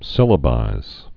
(sĭlə-bīz)